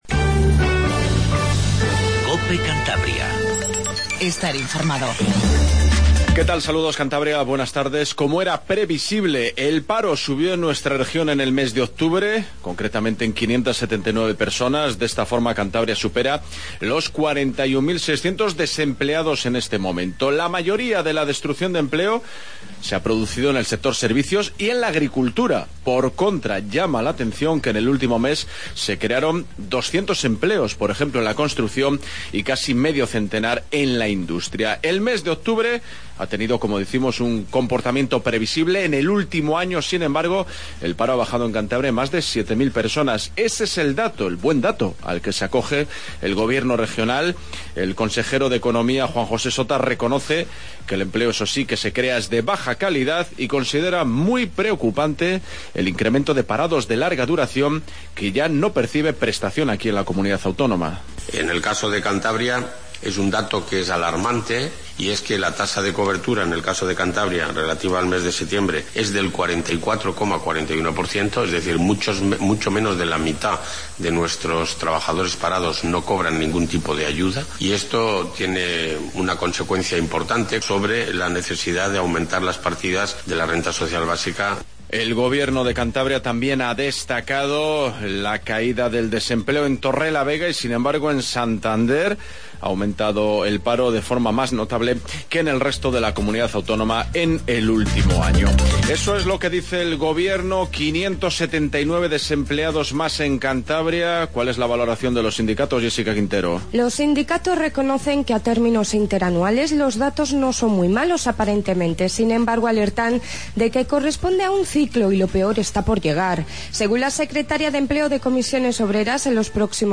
INFORMATIVO REGIONAL 14:20